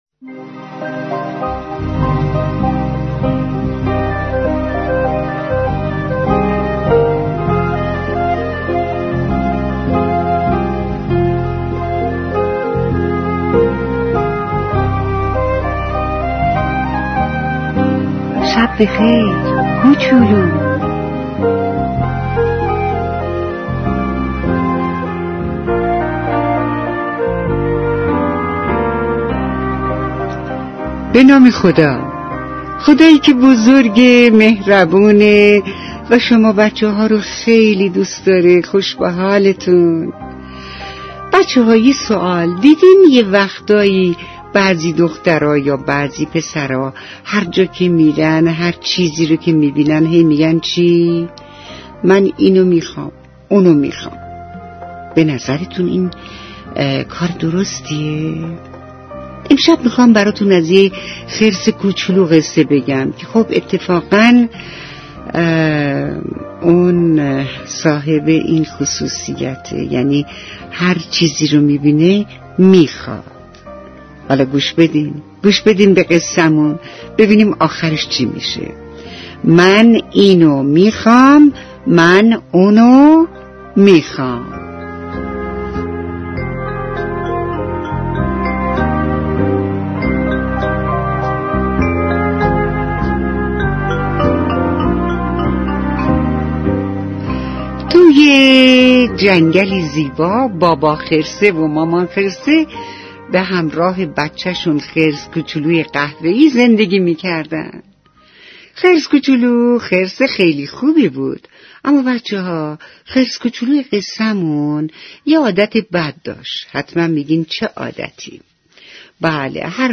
قصه های شب